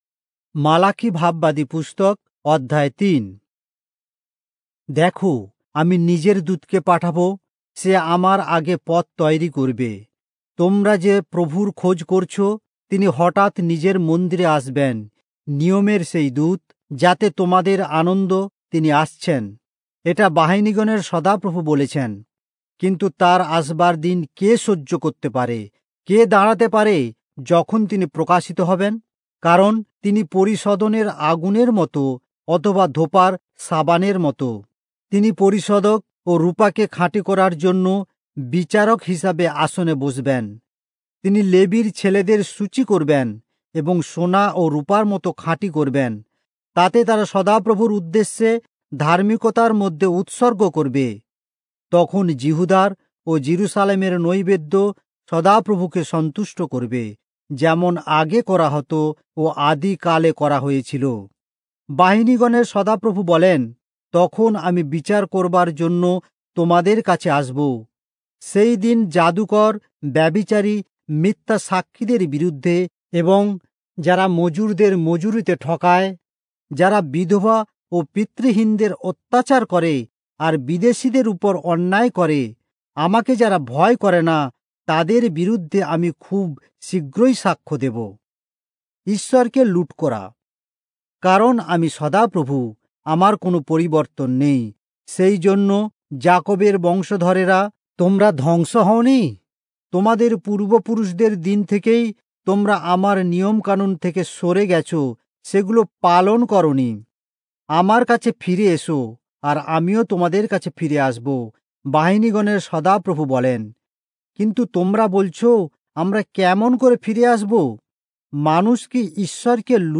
Bengali Audio Bible - Malachi 3 in Irvbn bible version